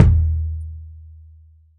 • Low Tom Sample D# Key 29.wav
Royality free tom one shot tuned to the D# note. Loudest frequency: 152Hz
low-tom-sample-d-sharp-key-29-OTe.wav